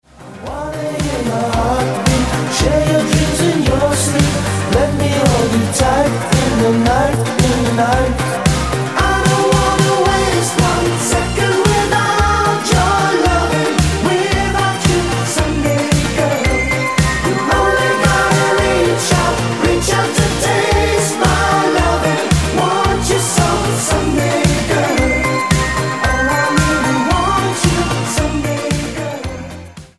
поп
спокойные